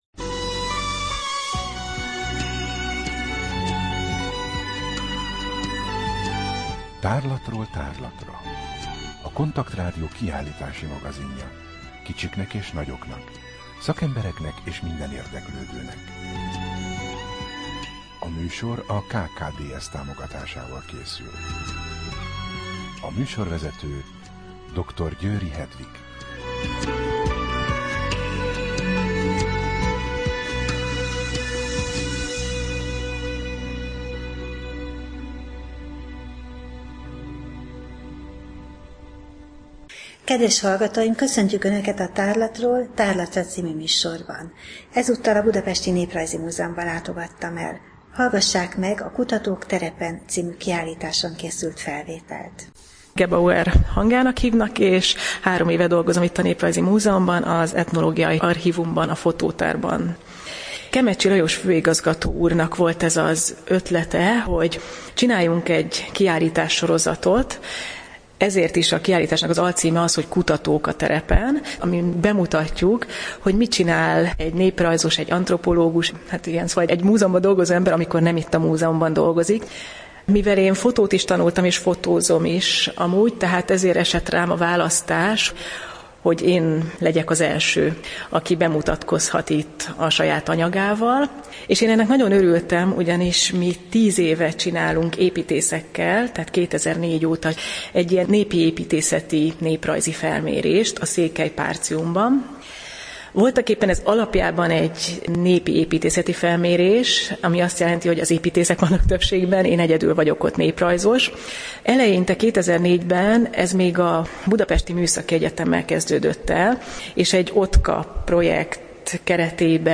Rádió: Tárlatról tárlatra Adás dátuma: 2014, April 28 Tárlatról tárlatra / KONTAKT Rádió (87,6 MHz) 2014. április 28. A műsor felépítése: I. Kaleidoszkóp / kiállítási hírek II. Bemutatjuk / Kutatók a terepen I. Néprajzi Múzeum, Budapest A műsor vendége